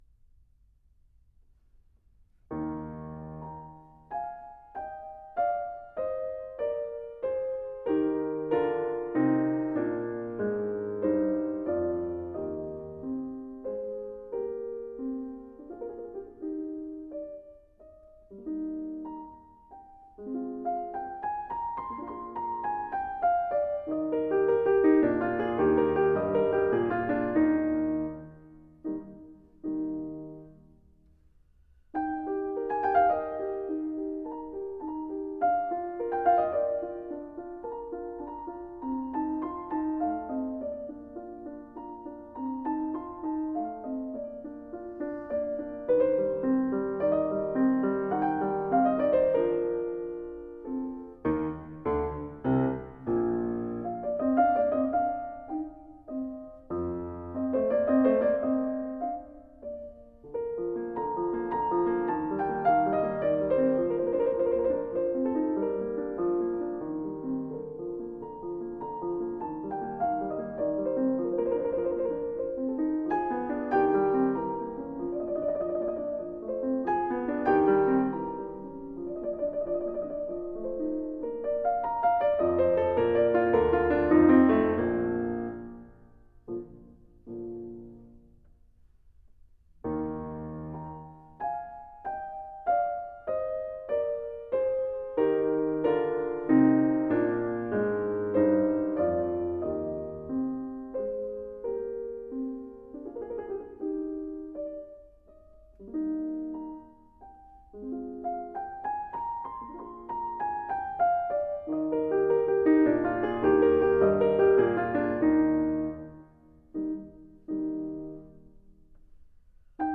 Piano sonata
A sonata written for a solo piano.